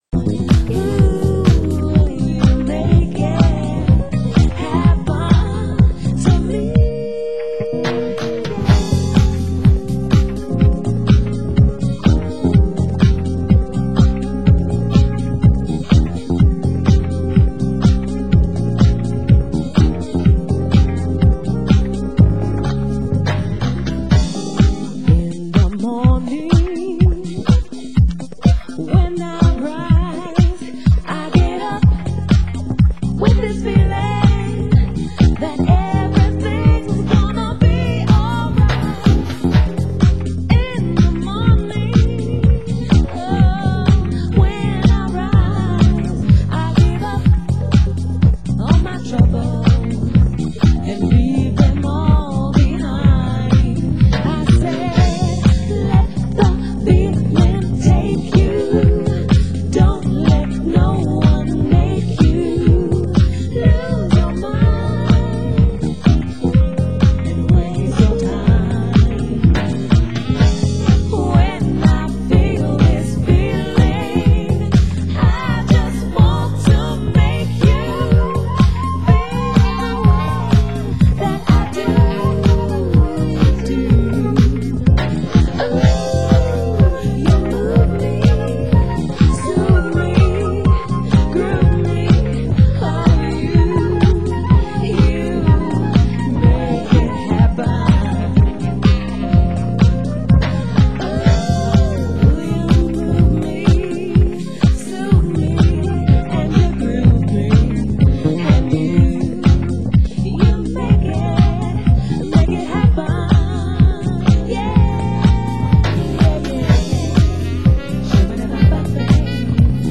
Genre: UK House
Vocal, Instrumental